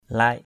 /laɪʔ/